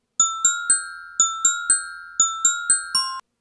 Soaring_hawk.ogg